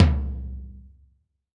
PTOM 2.wav